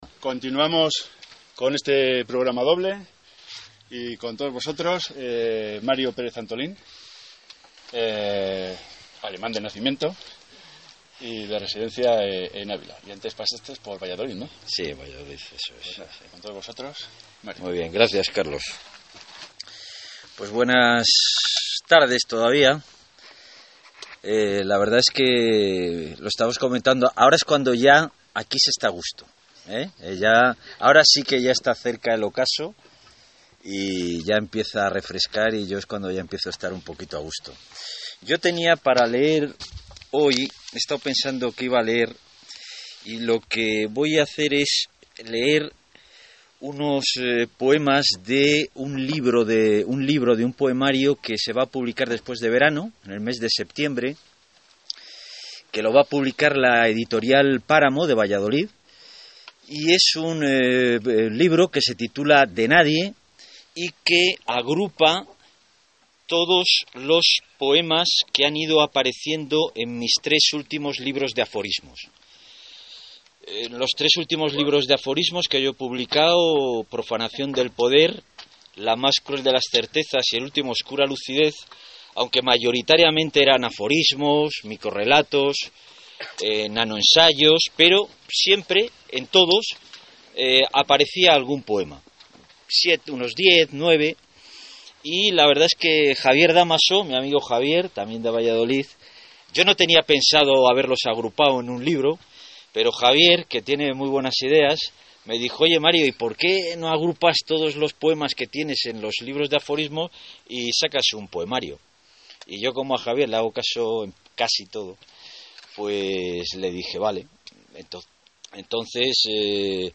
Centro de Arte y Naturaleza Cerro Gallinero.
recital_cerro_gallinero.02.mp3